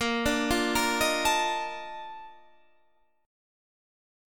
BbM11 Chord
Listen to BbM11 strummed